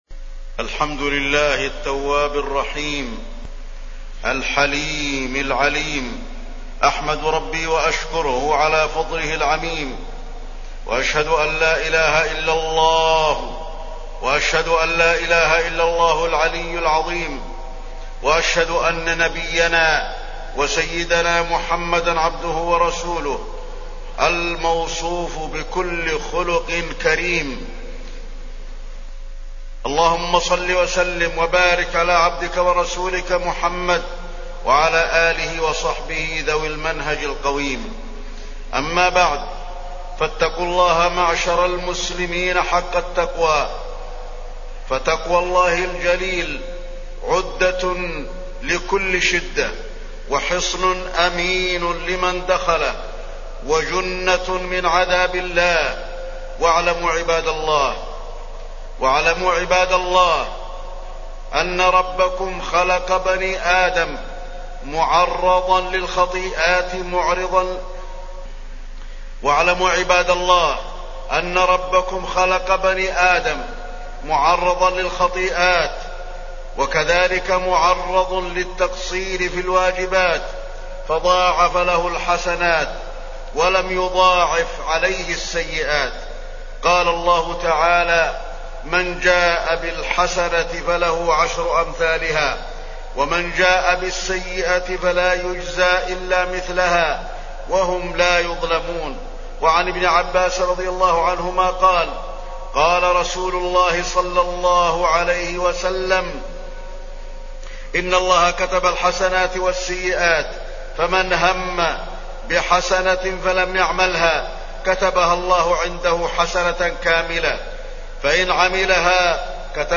تاريخ النشر ١٧ شوال ١٤٢٩ هـ المكان: المسجد النبوي الشيخ: فضيلة الشيخ د. علي بن عبدالرحمن الحذيفي فضيلة الشيخ د. علي بن عبدالرحمن الحذيفي إن الحسنات يذهبن السيئات